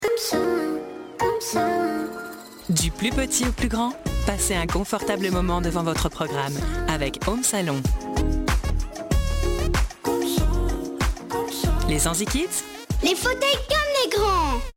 Bande annonce animé
30 - 50 ans - Mezzo-soprano